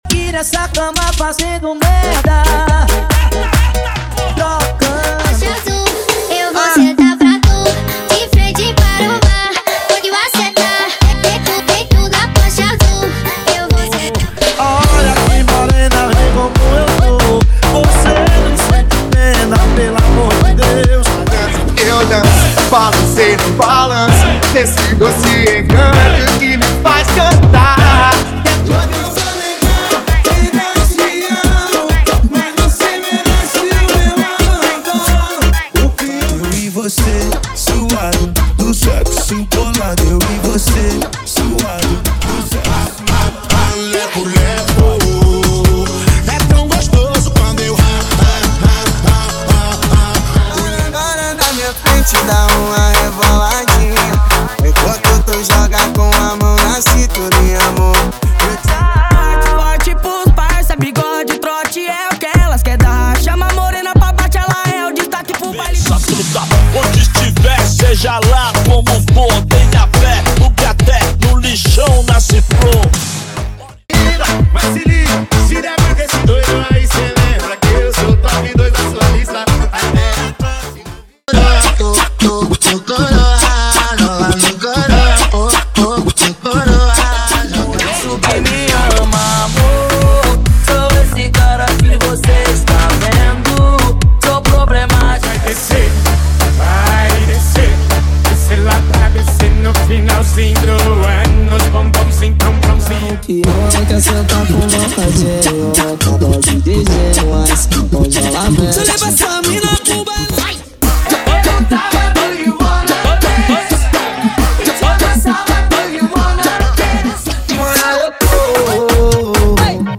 Sem Vinhetas